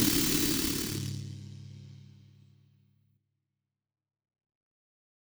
Index of /musicradar/impact-samples/Processed Hits
Processed Hits 05.wav